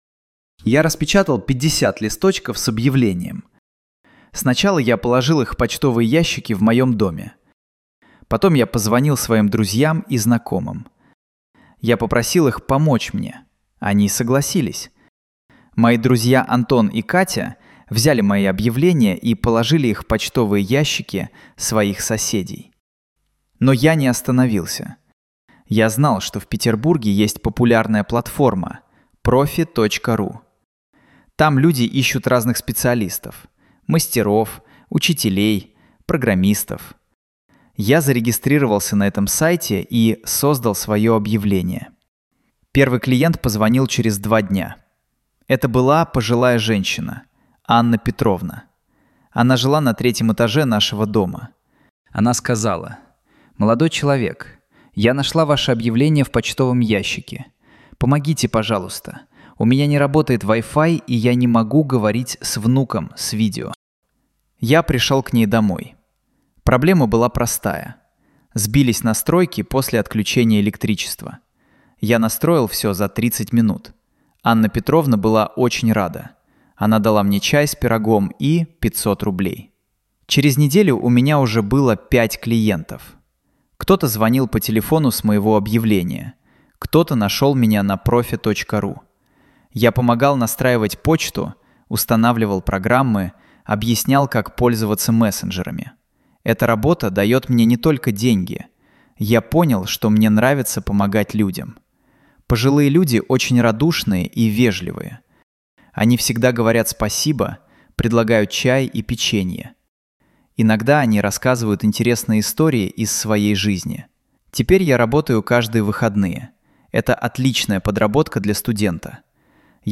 Écoutez une histoire authentique en russe avec traduction française et prononciation claire pour progresser rapidement.